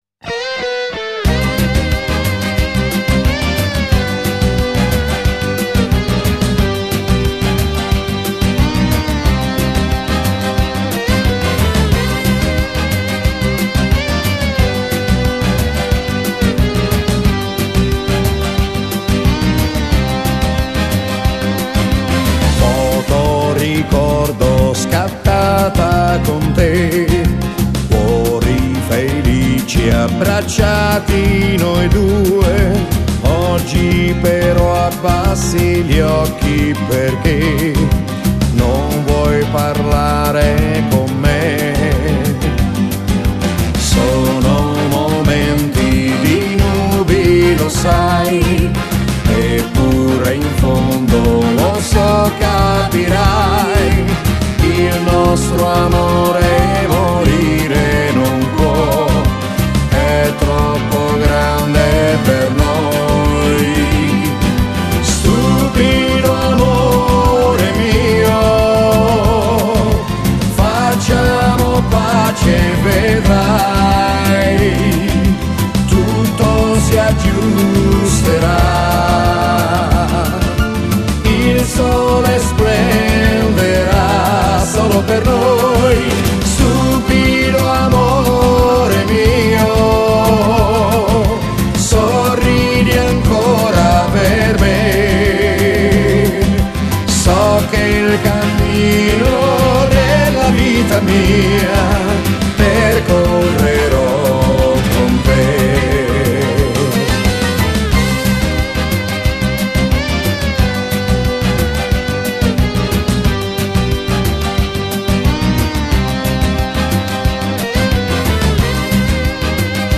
Genere: Rumba rock